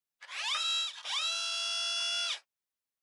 Зум объектива фотоаппарата